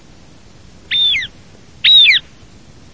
Albanella Reale
Circus cyaneus
Il richiamo è un rapido ciarlio ‘ki ki ki kiÂ¼’; emette anche un lamentoso ‘pii-i’.
Albanella-Reale-Circus-cyaneus.mp3